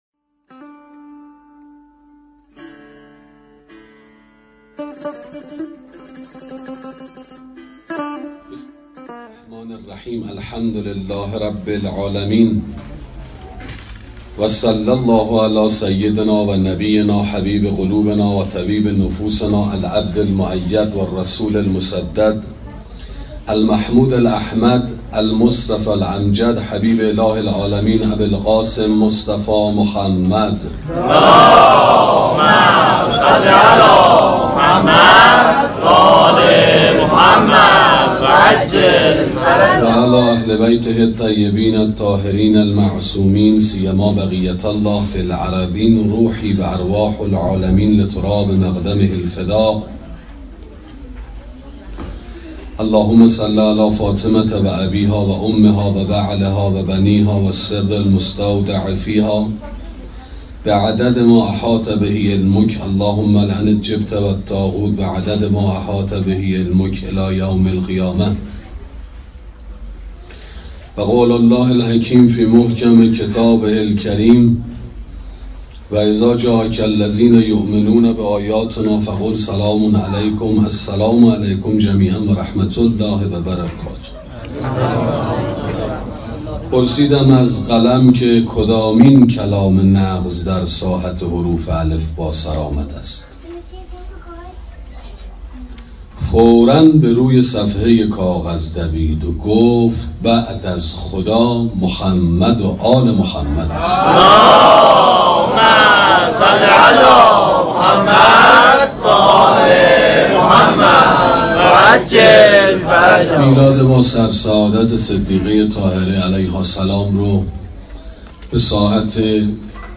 سخنرانی شناخت اقدامات حضرت زهرا (س) 2